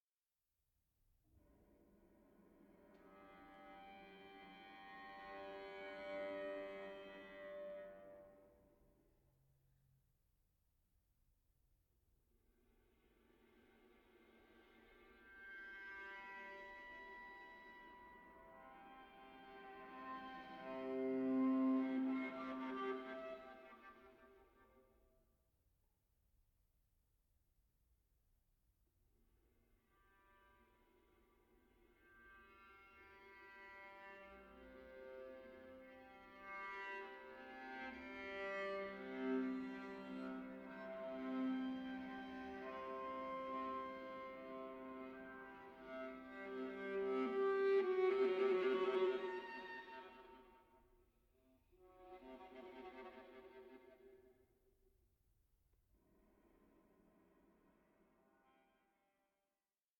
string quartet